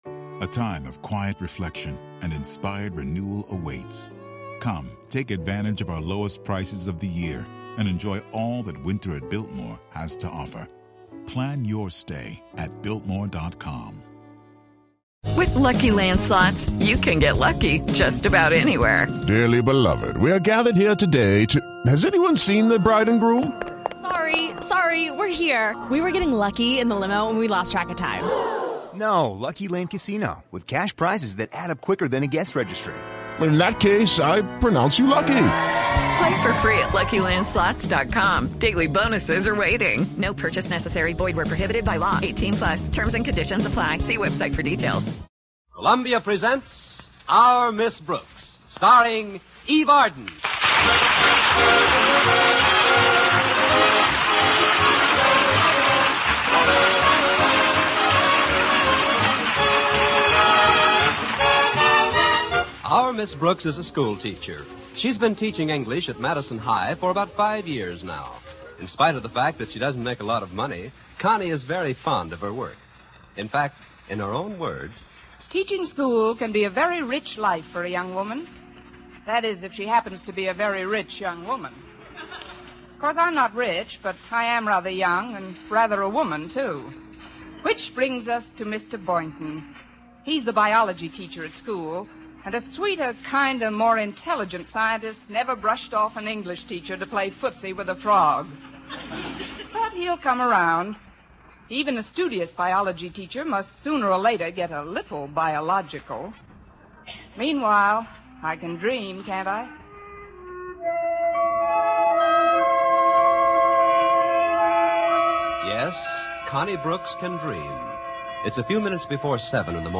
Our Miss Brooks was a beloved American sitcom that ran on CBS radio from 1948 to 1957.
The show starred the iconic Eve Arden as Connie Brooks, a wisecracking and sarcastic English teacher at Madison High School. Arden's portrayal of Miss Brooks was both hilarious and endearing, and she won over audiences with her quick wit and sharp one-liners The supporting cast of Our Miss Brooks was equally memorable. Gale Gordon played the uptight and pompous Principal Osgood Conklin, Richard Crenna played the dimwitted but lovable student Walter Denton, and Jane Morgan played Miss Brooks' scatterbrained landlady, Mrs. Davis.